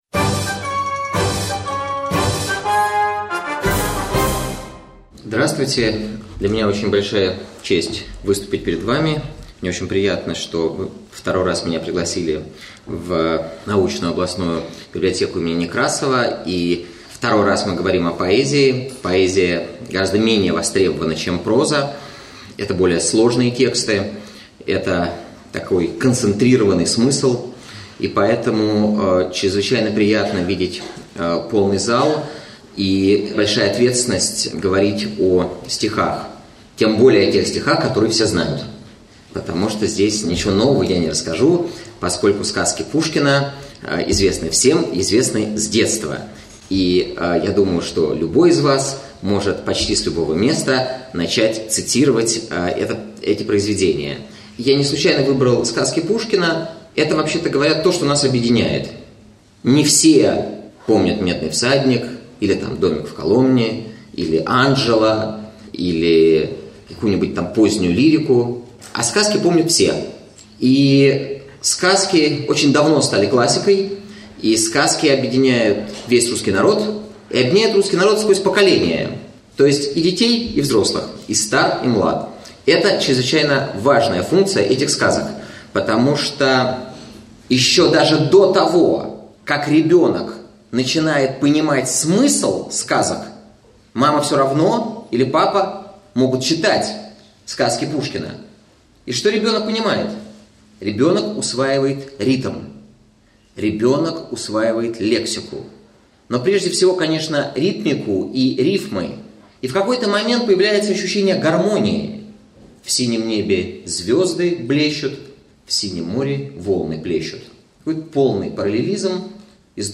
Аудиокнига Сказки Пушкина.
Прослушать и бесплатно скачать фрагмент аудиокниги